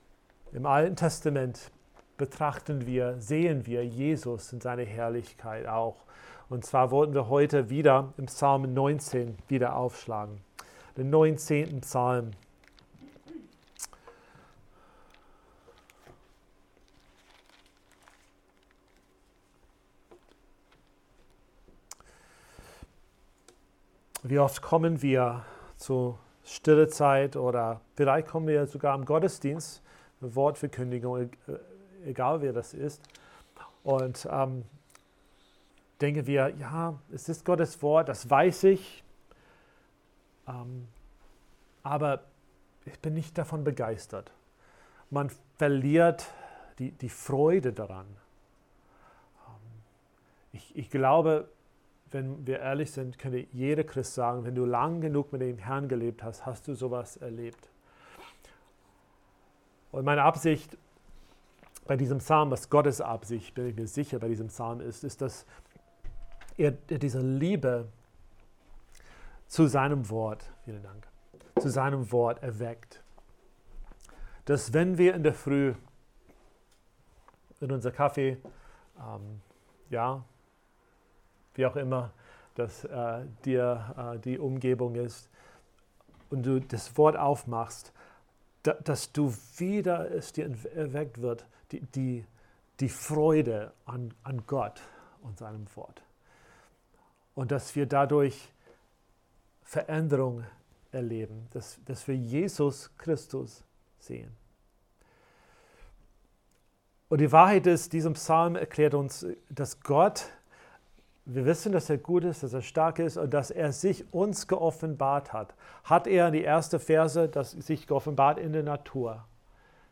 Gottesdienst am 31.05.2020